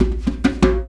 Index of /90_sSampleCDs/Roland LCDP14 Africa VOL-2/PRC_Af.Hand Drm2/PRC_Djembe Drums
PRC HAND D0A.wav